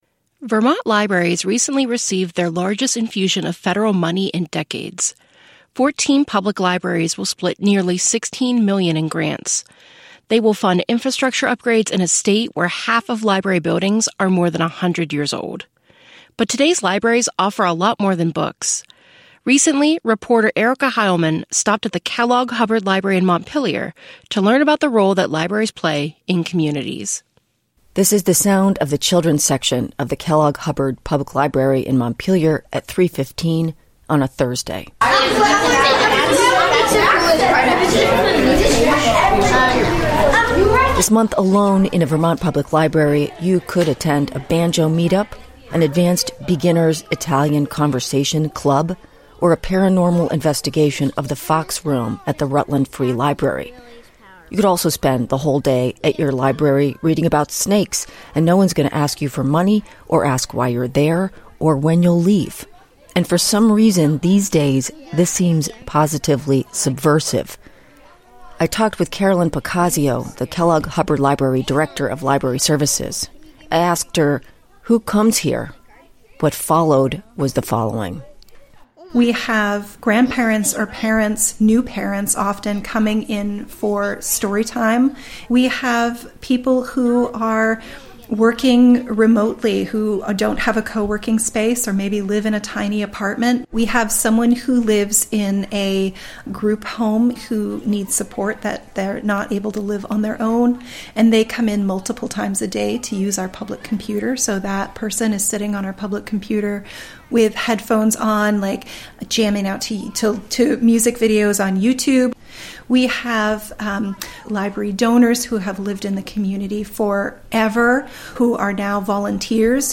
Local news, reporting and newscasts from Vermont Public.